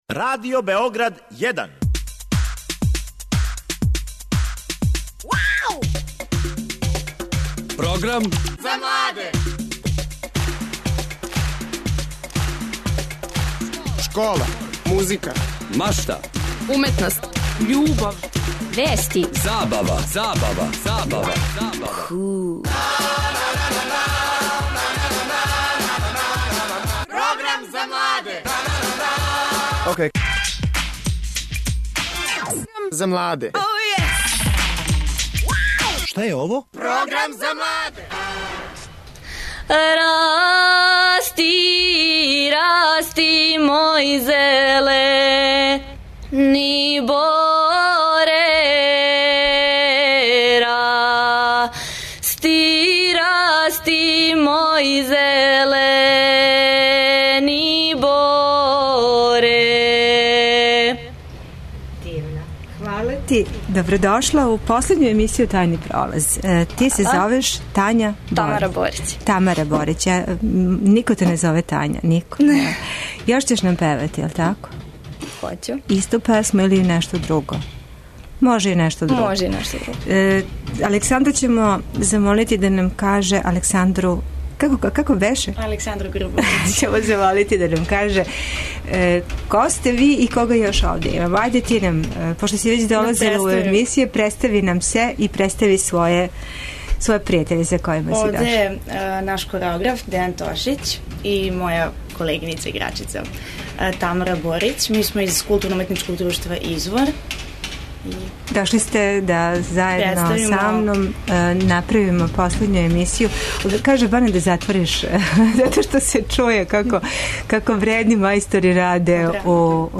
Фрула ће засвирати, а иако је студио мали, и мало коло ће се окренути...